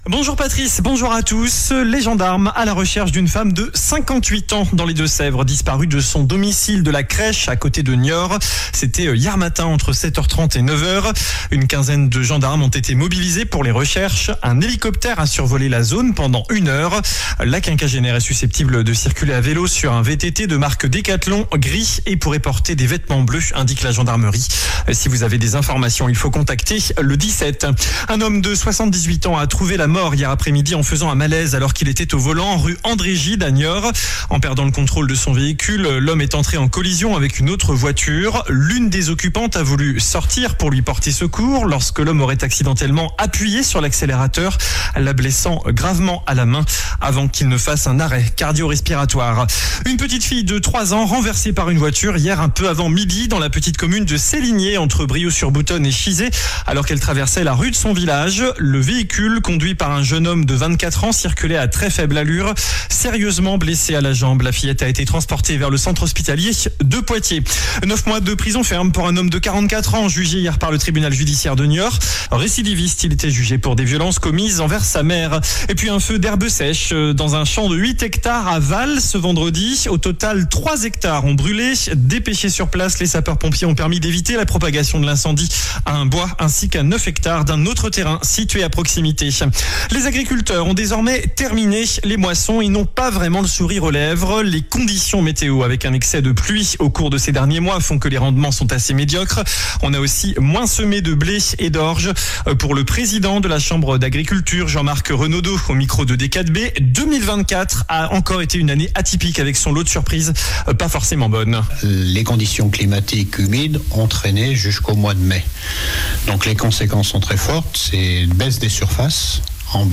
JOURNAL DU SAMEDI 17 AOÛT